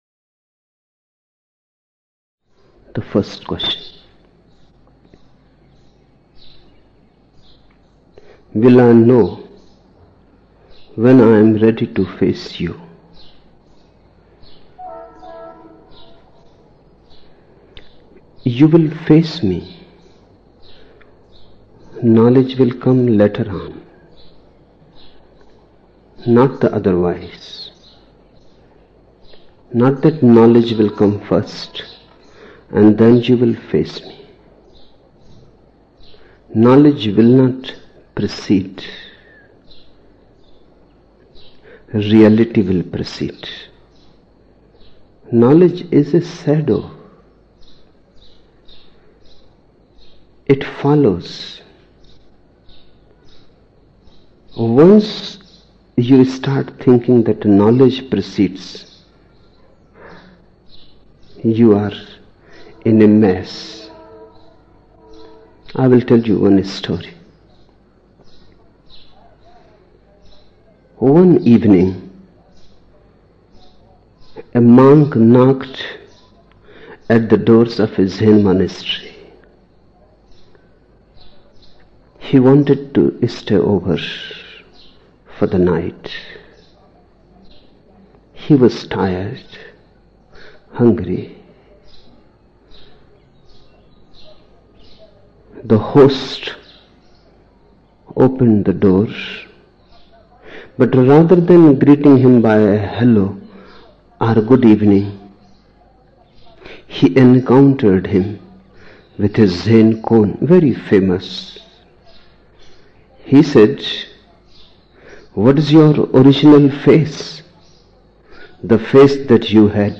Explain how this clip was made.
7 November 1975 morning in Buddha Hall, Poona, India